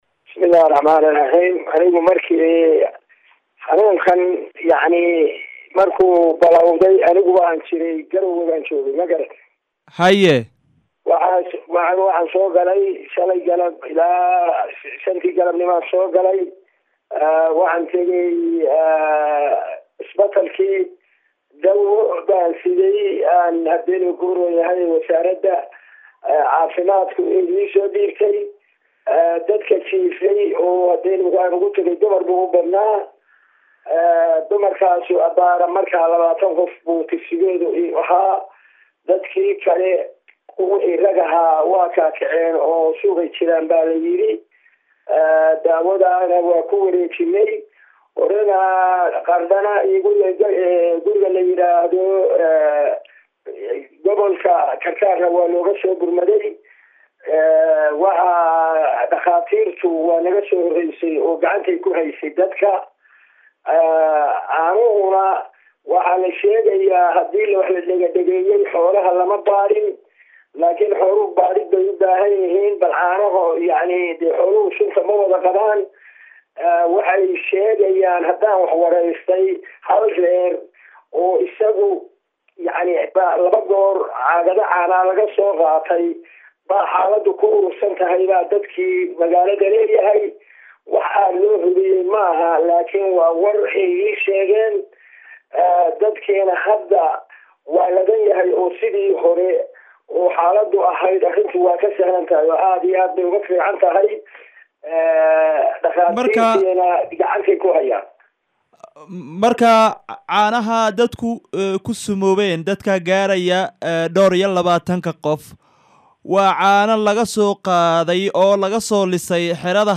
Gudoomiyaha Xingalool oo ka waramaya dad ku wax yeeloobay caano ay cabeen